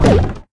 barrel_roll_02.ogg